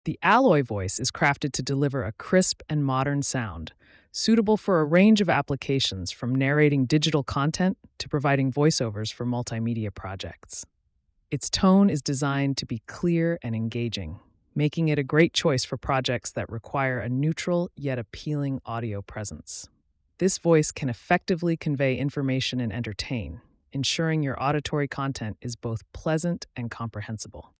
We have 6 ai voices to choose from
The “Alloy” voice is crafted to deliver a crisp and modern sound, suitable for a range of applications from narrating digital content to providing voiceovers for multimedia projects. Its tone is designed to be clear and engaging, making it a great choice for projects that require a neutral yet appealing audio presence.